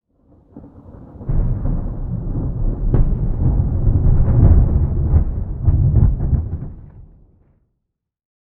pre_storm_2.ogg